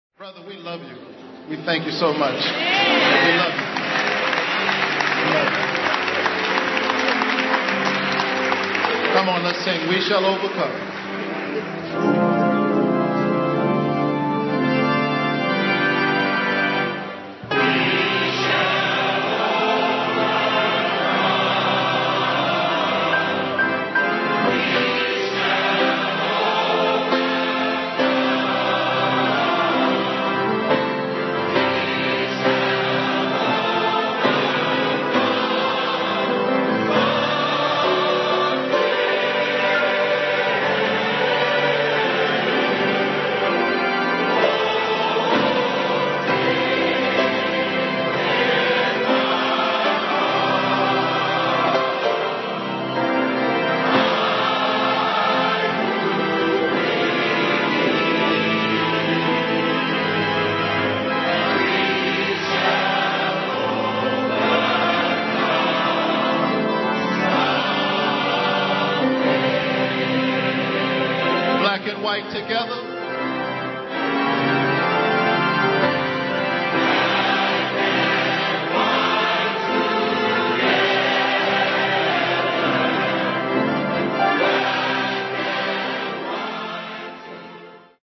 Совсем как некогда голос неистового Пита Сигера — голос Барака Обамы тонет в общем хоре единомышленников: